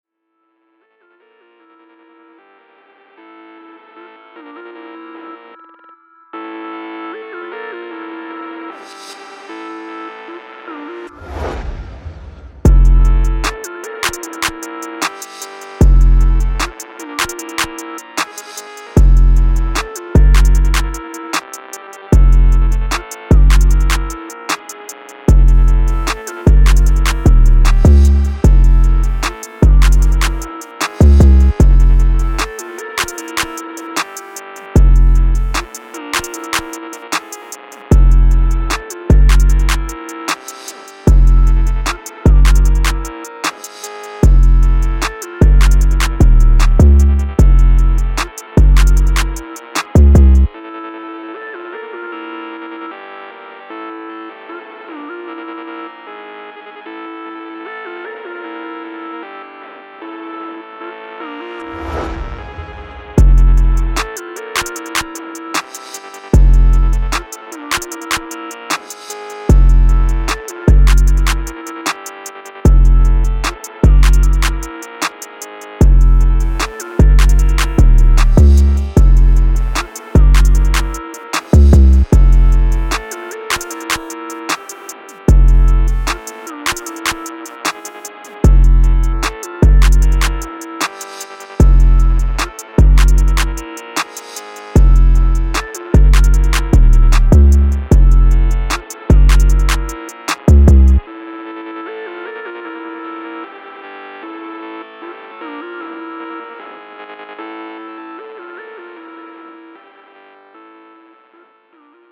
Cold, Dark, Energetic, Sexy
Drum, Heavy Bass, Piano, Strings